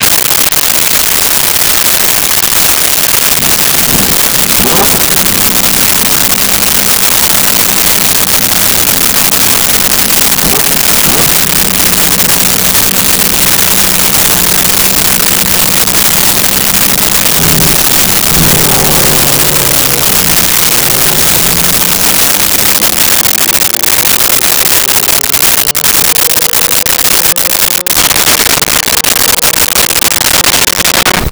Race Car Idle Rev Away
Race Car Idle Rev Away.wav